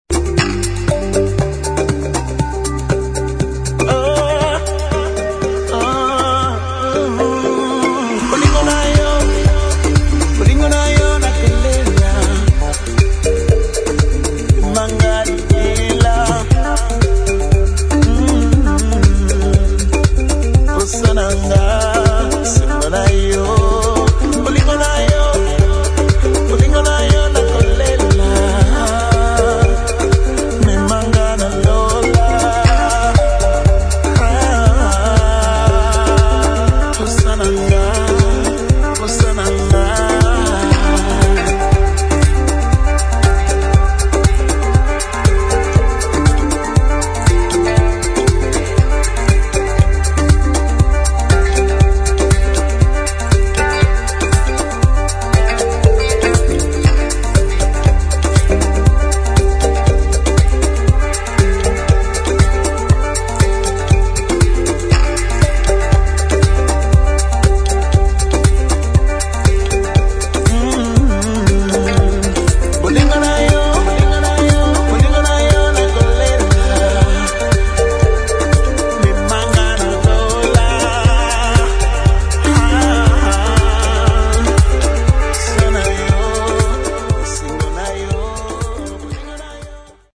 [ HOUSE / TECHNO ]
温かいサブベースとグルーヴィービーツのタイトル・トラック